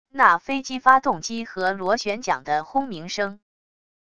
那飞机发动机和螺旋桨的轰鸣声wav音频